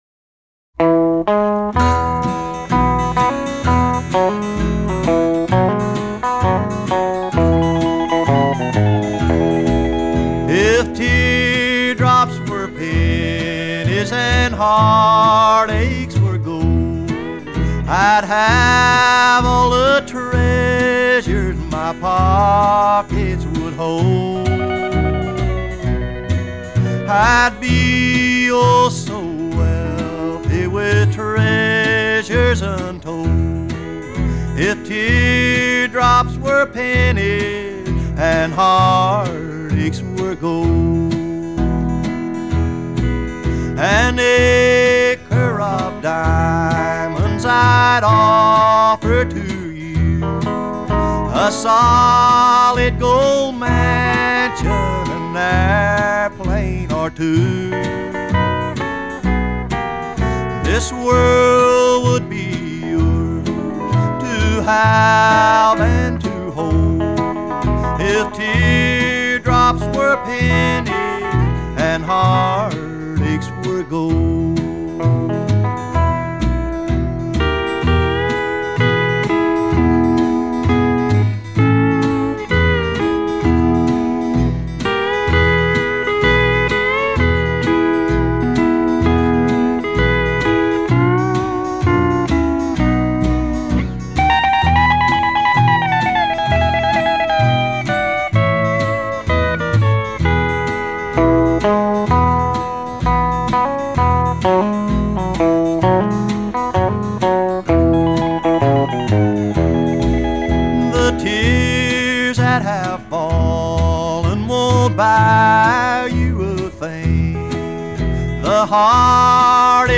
американский кантри-певец.